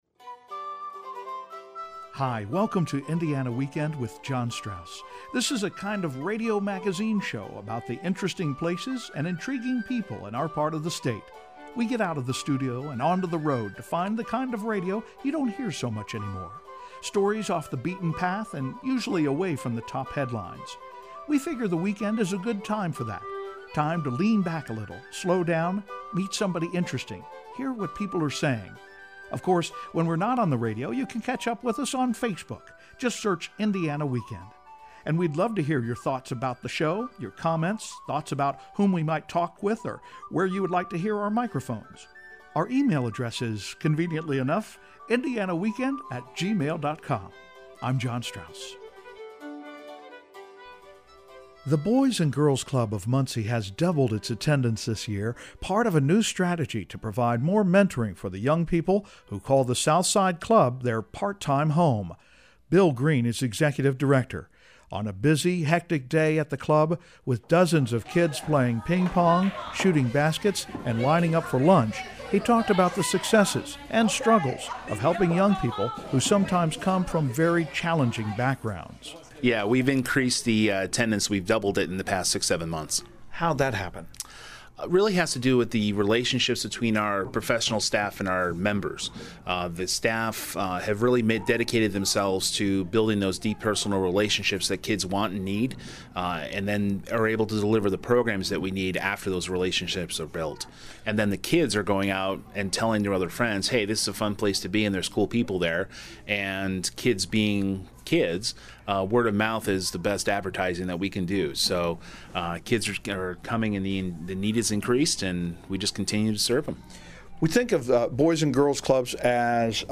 On a busy, hectic day at the club, with dozens of kids playing ping-pong, shooting baskets and lining up for lunch, he talked about the successes – and struggles of helping young people who sometimes come from very challenging backgrounds.